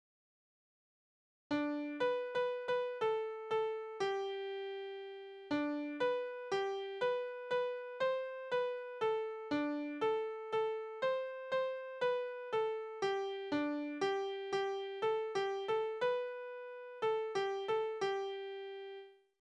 Wiegenlieder: Das Lämmchen im Holz
Tonart: G-Dur
Taktart: 4/4
Tonumfang: kleine Septime
Anmerkung: - Nutzung von Triolen